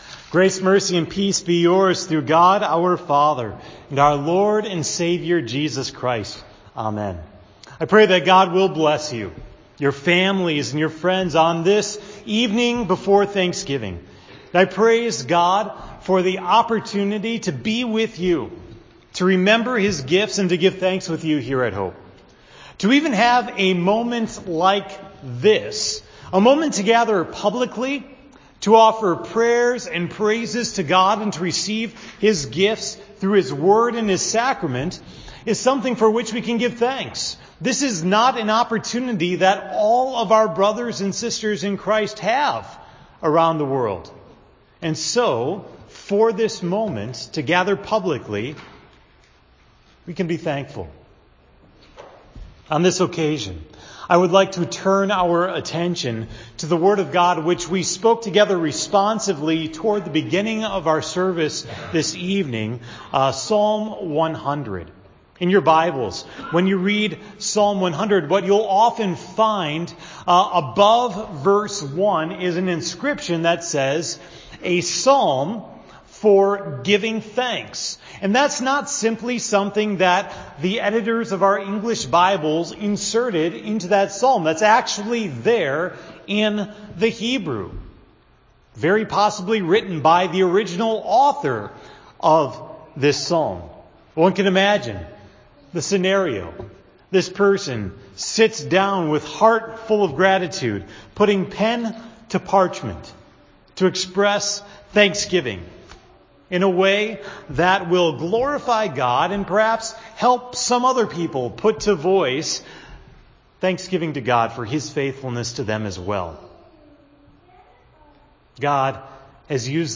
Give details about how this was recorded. The sermon for November 22, 2017 (Thanksgiving Eve) at Hope Text: Psalm 100